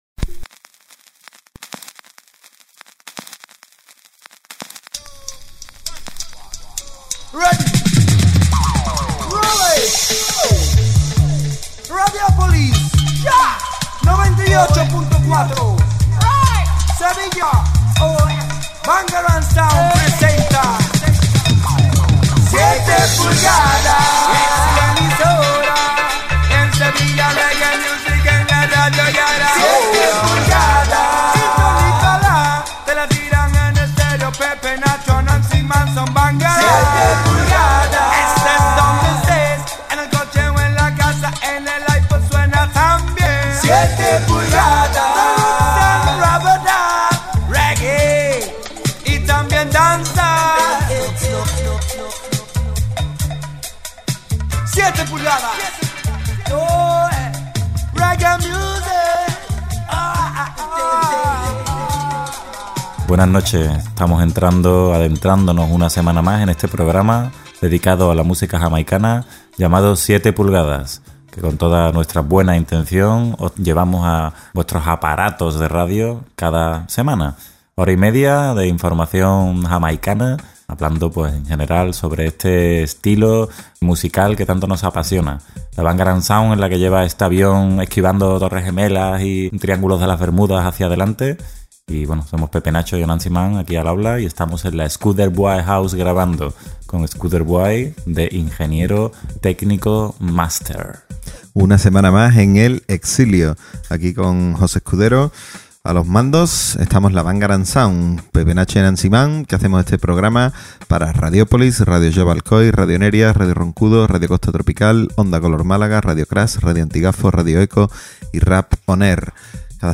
7PULGADAS es un programa dedicado a la música reggae y dancehall dirigido y presentado por la BANGARANG SOUND